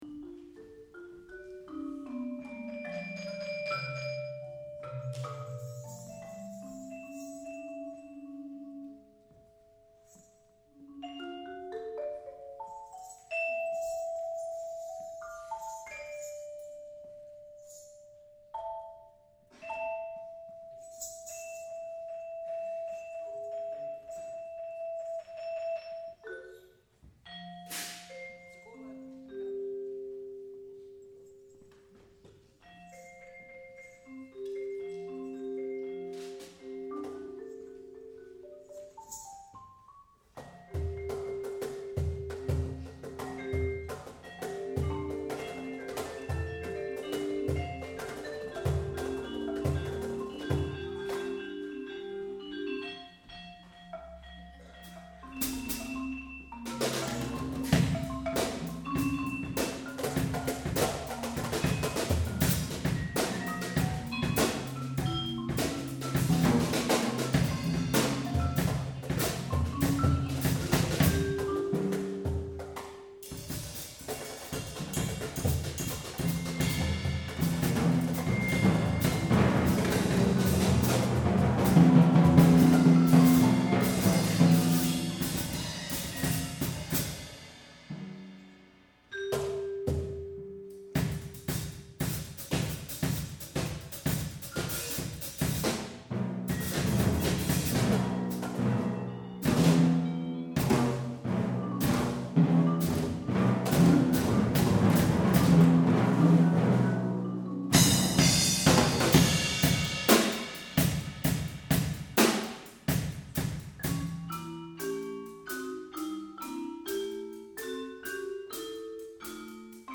Chamber – Percussion Quintet